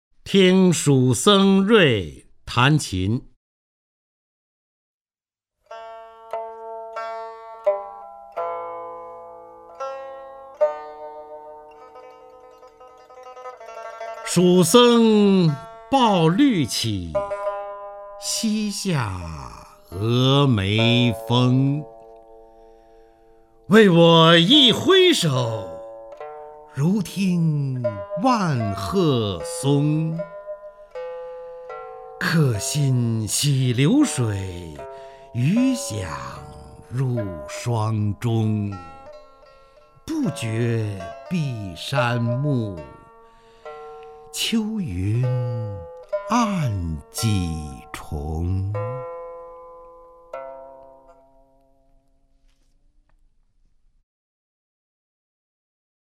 方明朗诵：《听蜀僧濬弹琴》(（唐）李白) （唐）李白 名家朗诵欣赏方明 语文PLUS